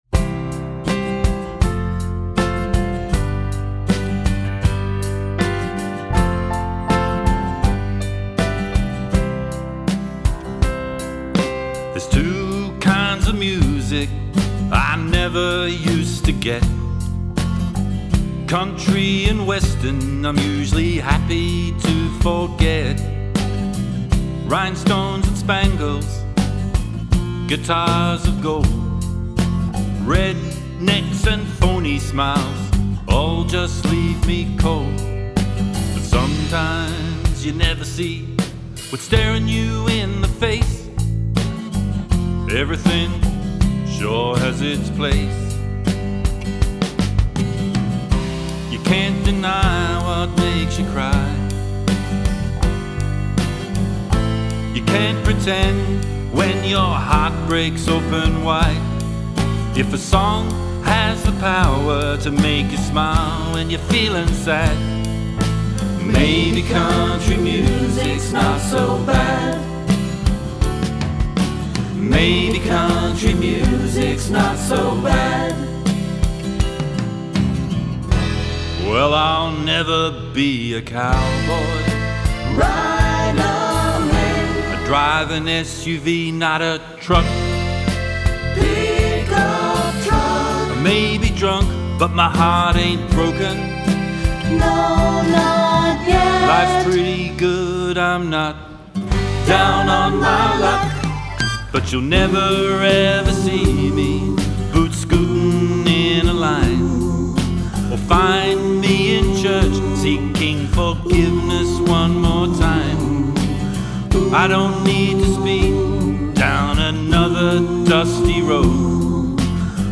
Drums.
Vocals & Keyboards.
Vocals, Acoustic Guitar & Bass.
Mandolin & Harmonica.
Slide Guitar.
Electric Guitar.